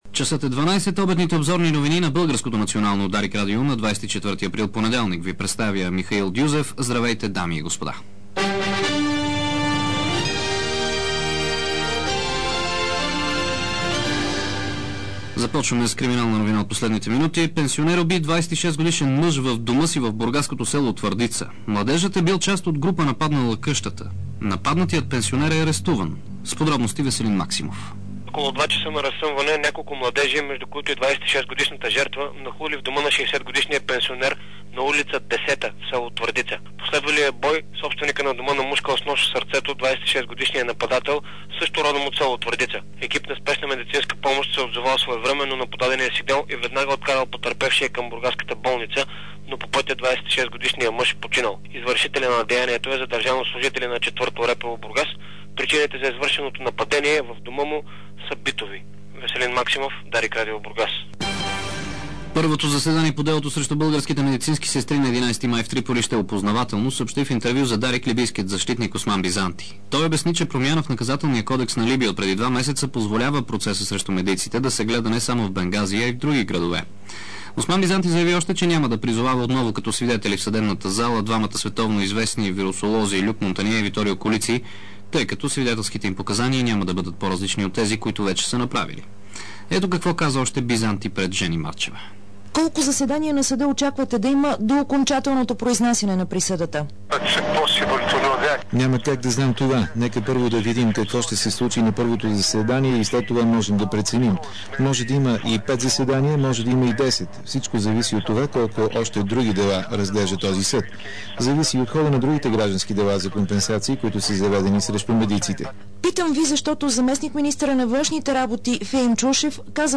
DarikNews audio: Обедна информационна емисия 24.04.2006